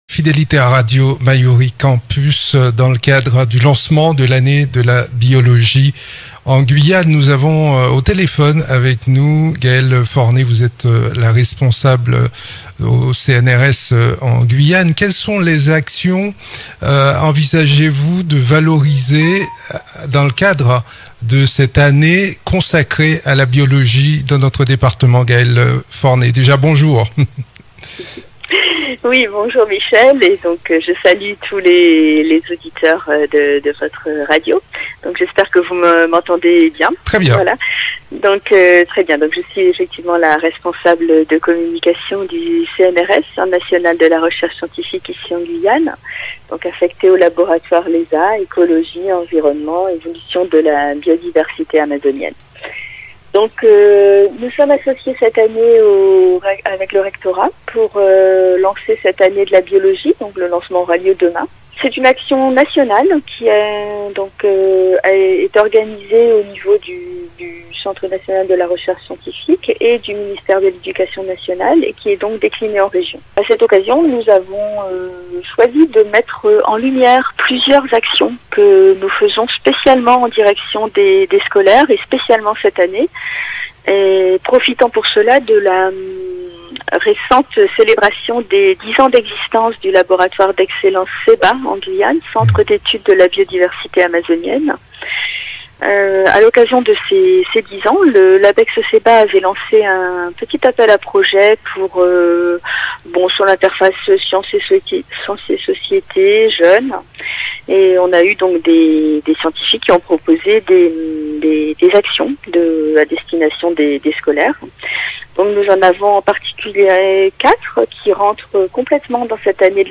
Elle répondait par téléphone à nos questions en direct de Kourou.